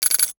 NOTIFICATION_Glass_06_mono.wav